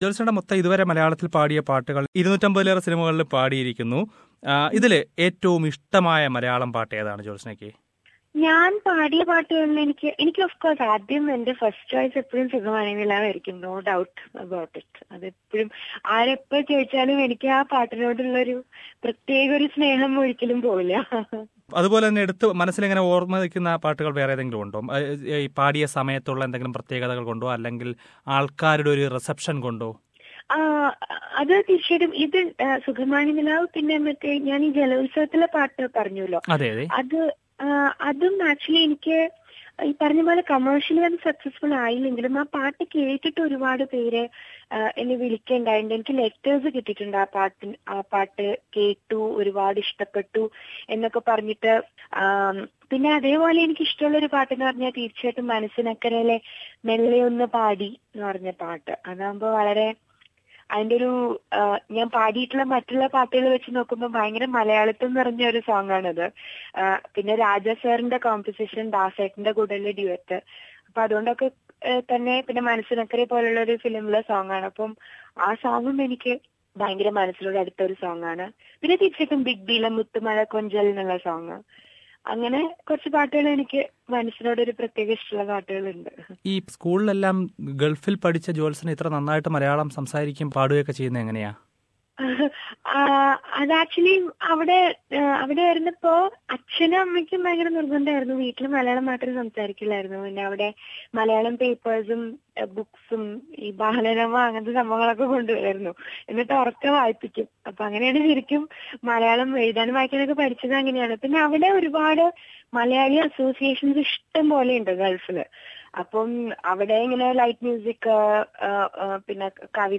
Discrimination in Malayalam Music Industry is a Reality: Jyotsna (Interview Part 2)